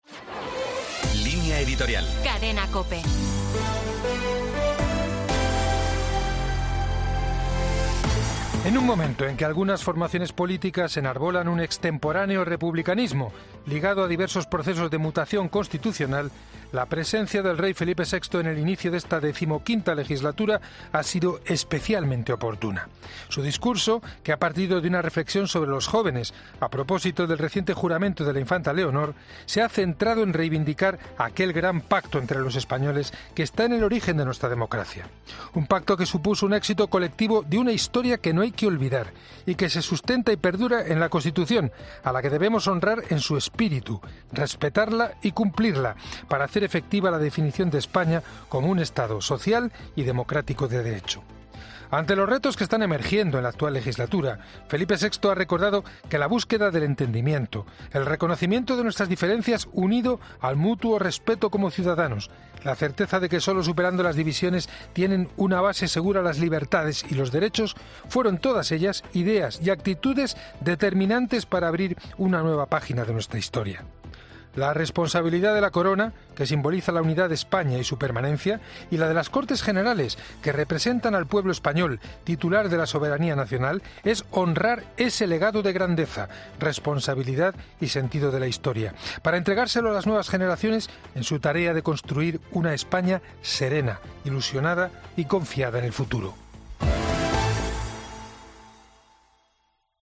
Carlos Herrera, director y presentador de 'Herrera en COPE', comienza el programa de este jueves analizando las principales claves de la jornada que pasan, entre otras cosas, por el contraste del discurso de Felipe VI y Francina Armengol.